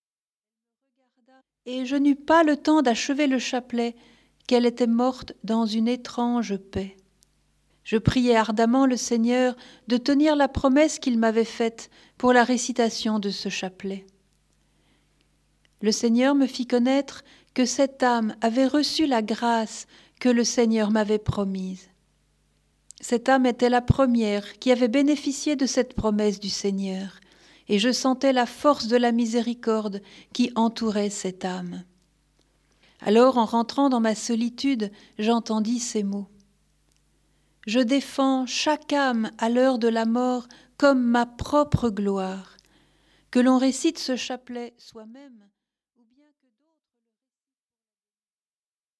Prières, chants, et enseignements.